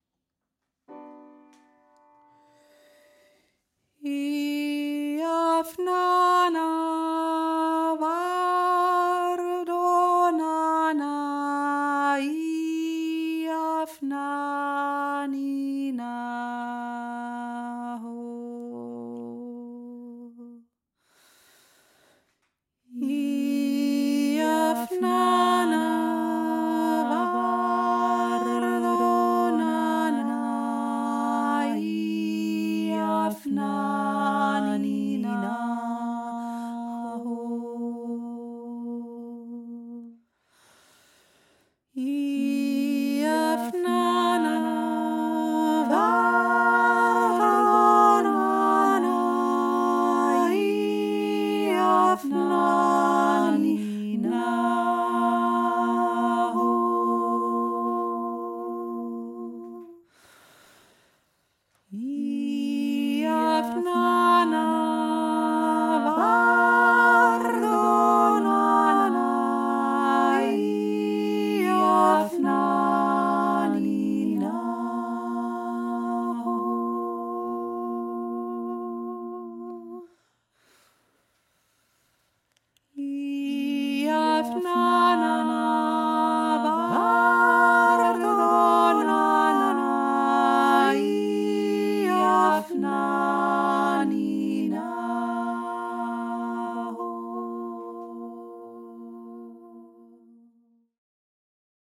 IAVNANA - georgisches Heilungslied
IAVNANA obere Stimme